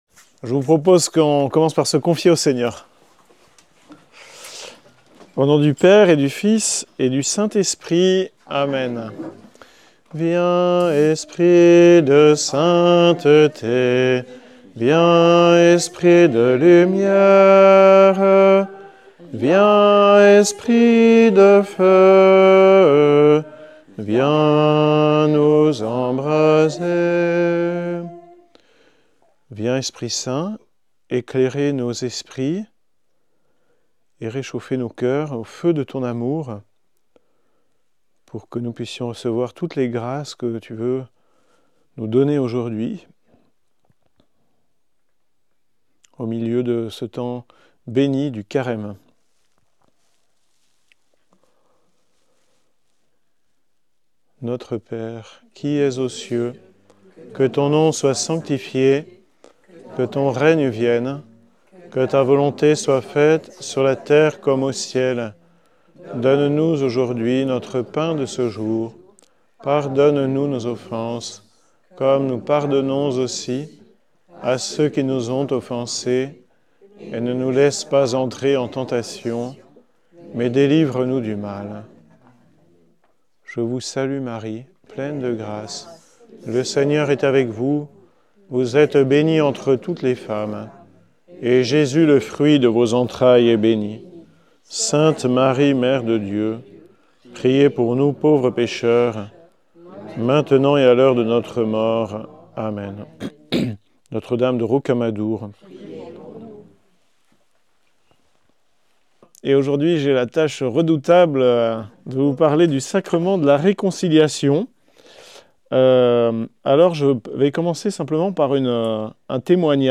Le sacrement du Pardon : Conférence